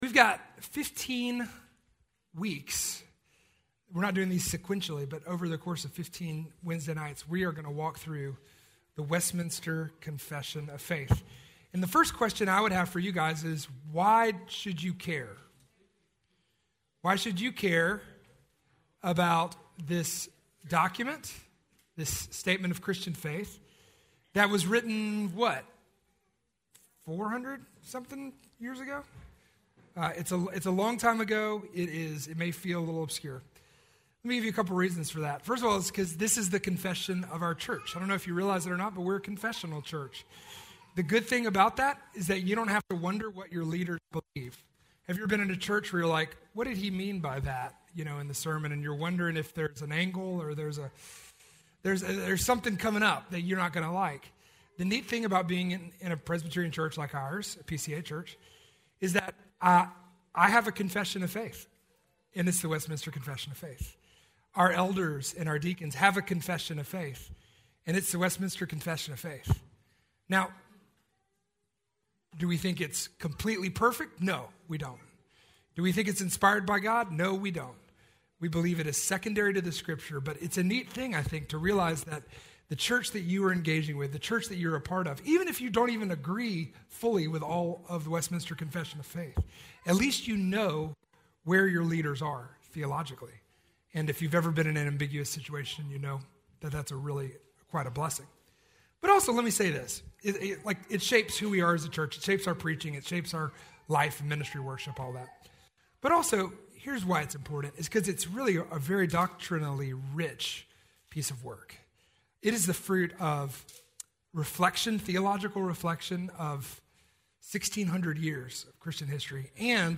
Westminster Confession of Faith Teaching